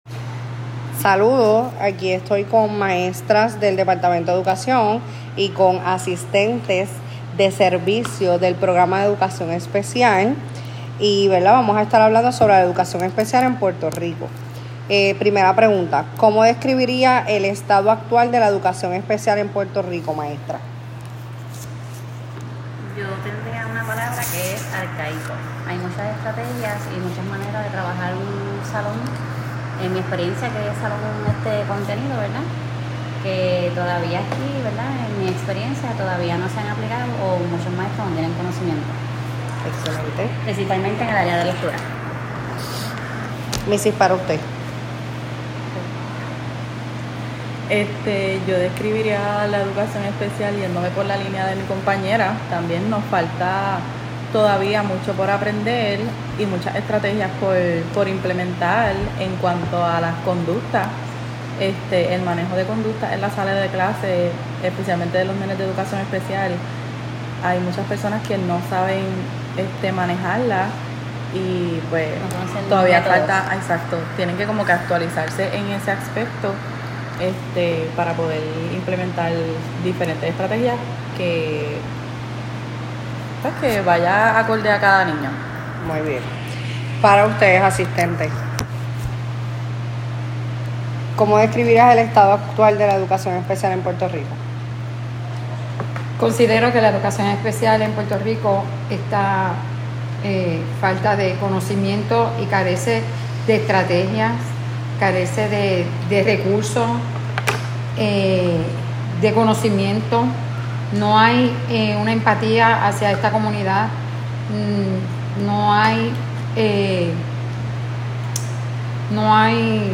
Hay testimonio de las maestras y otros empleados actuales del sistema publico educativo.